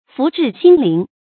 福至心靈 注音： ㄈㄨˊ ㄓㄧˋ ㄒㄧㄣ ㄌㄧㄥˊ 讀音讀法： 意思解釋： 福氣一來；心也變得靈巧起來。